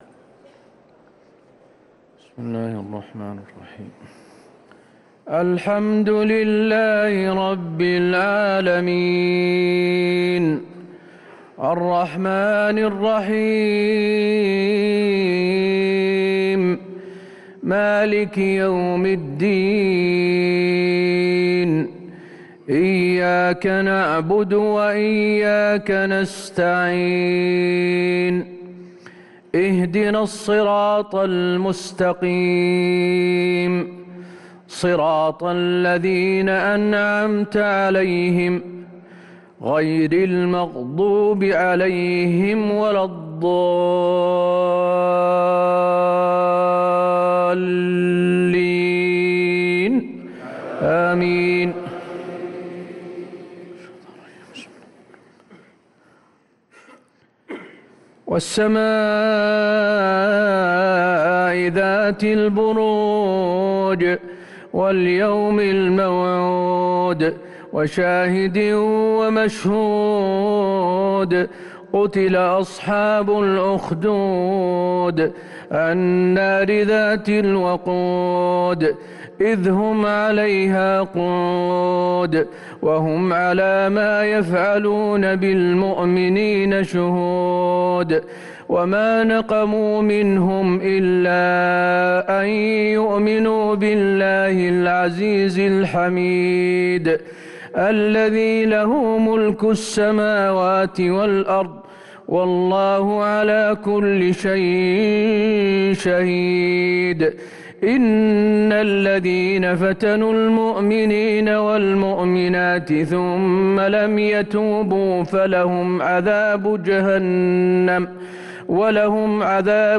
صلاة العشاء للقارئ حسين آل الشيخ 23 رمضان 1444 هـ
تِلَاوَات الْحَرَمَيْن .